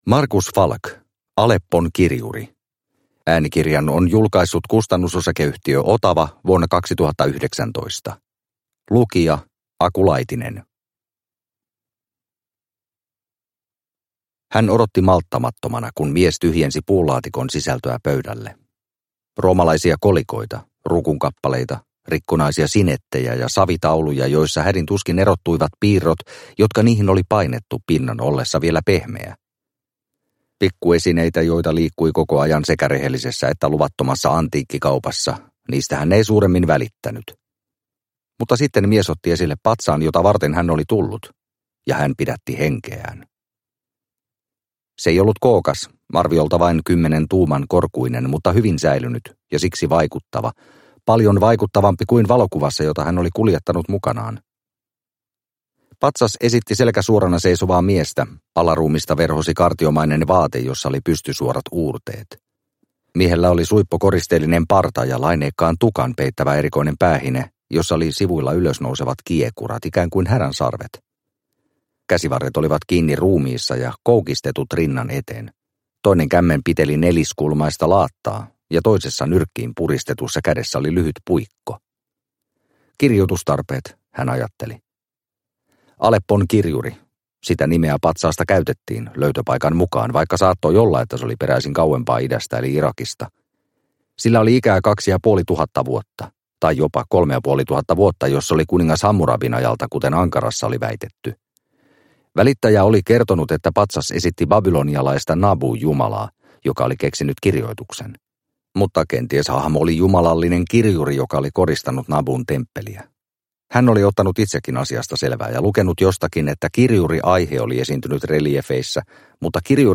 Aleppon kirjuri – Ljudbok – Laddas ner